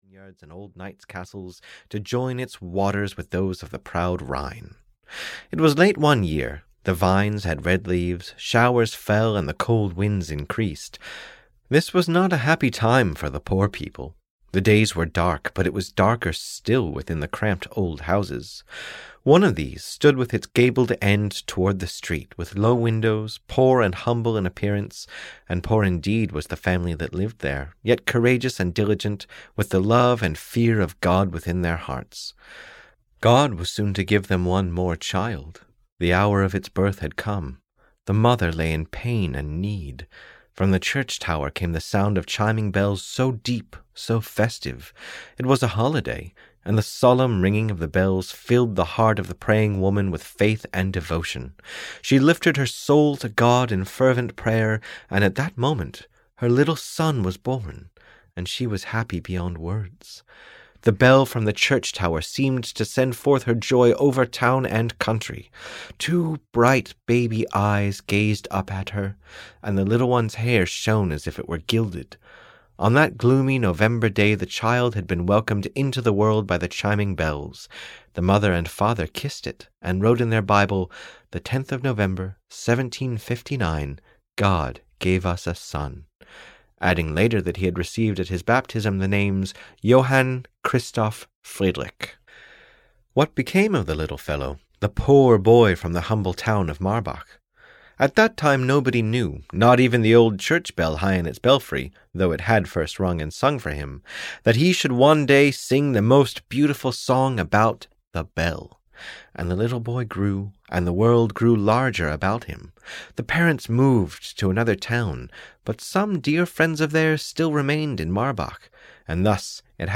The Old Church Bell (EN) audiokniha
Ukázka z knihy